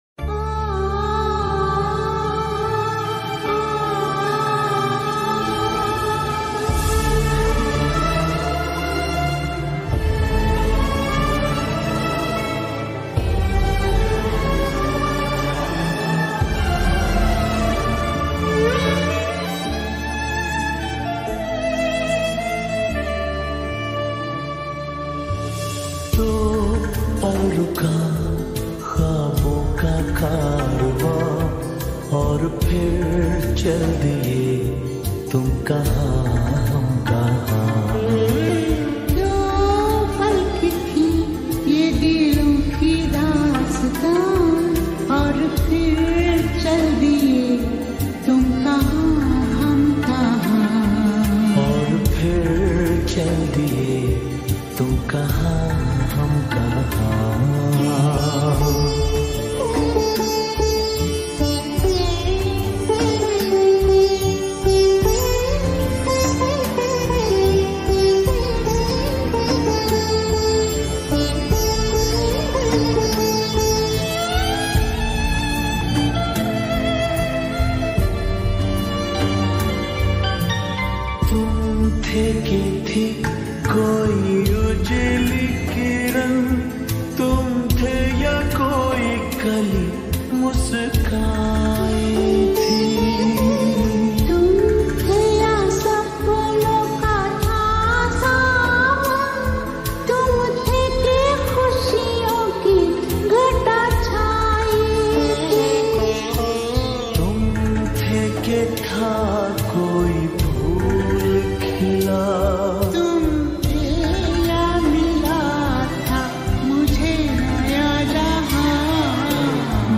full sad song